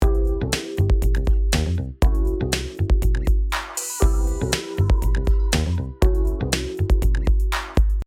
I then added a steep low cut to knock out the flabby sub-bass the low shelving boost had accentuated.
You can definitely hear how these minor changes have livened up the sound.
EQ After.mp3